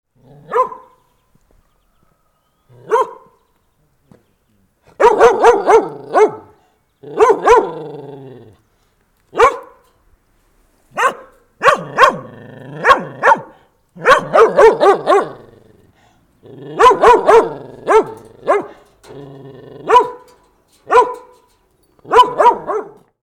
Loud Watchdog Barking In Neighborhood Sound Effect
A loud watchdog barking in a neighborhood sound effect, perfect for urban ambience, backyard scenes, security alerts, village environments, films, games, and multimedia projects. Dog sounds.
Loud-watchdog-barking-in-neighborhood-sound-effect.mp3